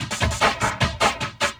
45 LOOP 02-L.wav